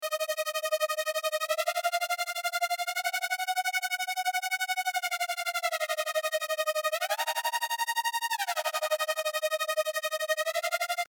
ZODIAC: DRUM & BASS
Lumosphere_Kit_D#min - Lead